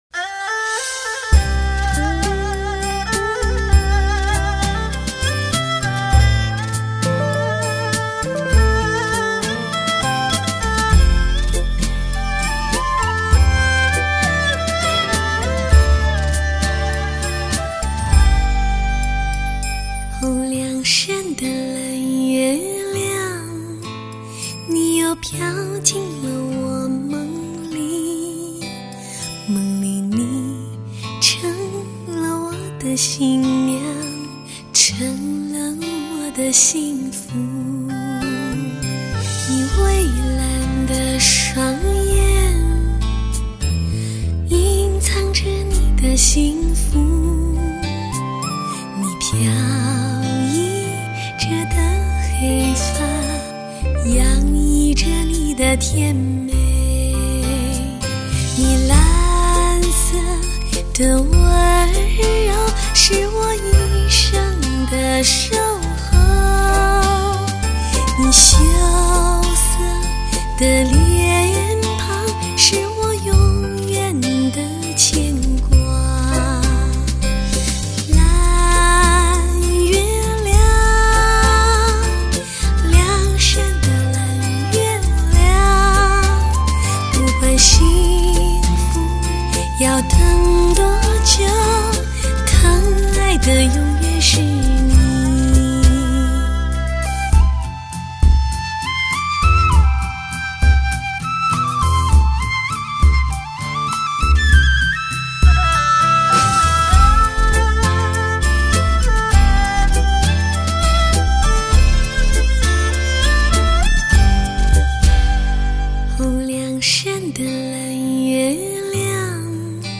甜腻柔美的情思 飘渺优美的歌声
HI-FI精品 澎湃动感 清纯响亮……